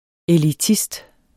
Udtale [ eliˈtisd ]